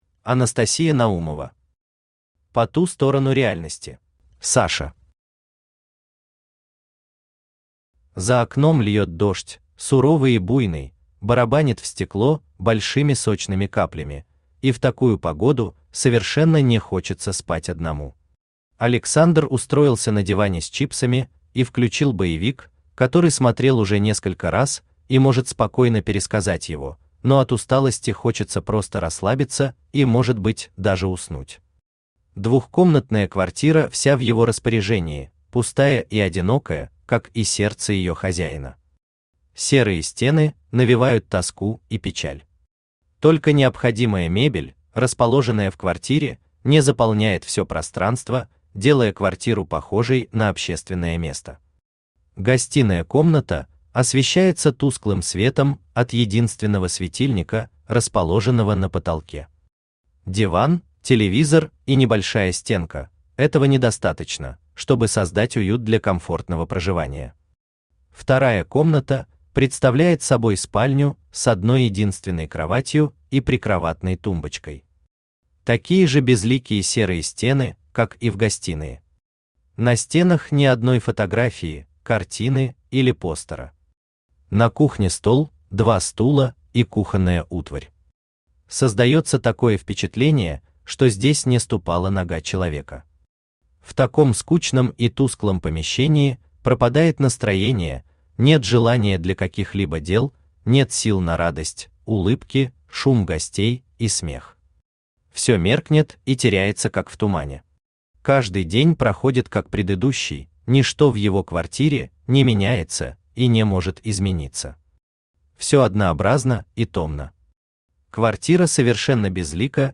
Аудиокнига По ту сторону реальности | Библиотека аудиокниг
Aудиокнига По ту сторону реальности Автор Анастасия Наумова Читает аудиокнигу Авточтец ЛитРес.